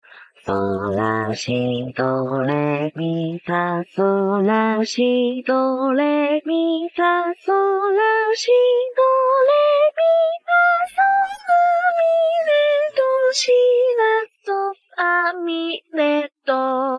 幸JPVCV_暖かい（soft）                       DL
soft_B3        （例：- あS）
falsetto_C5   （例：- あF）